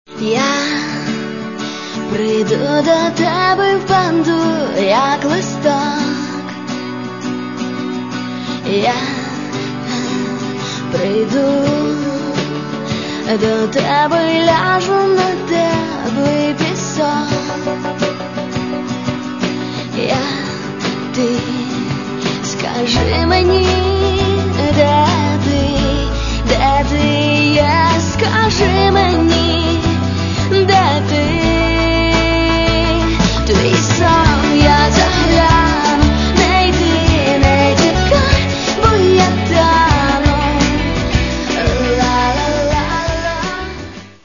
Каталог -> Поп (Легкая) -> Лирическая